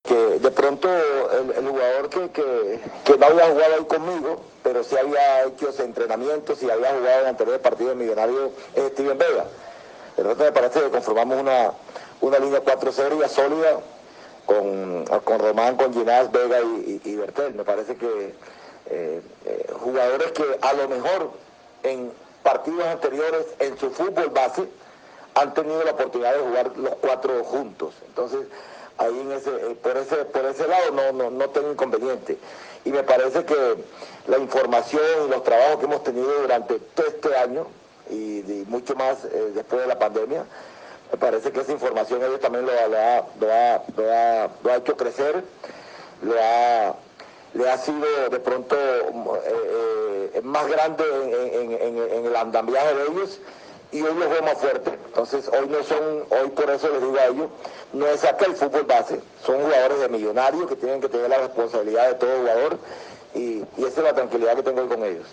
(Alberto Gamero, director técnico de Millonarios)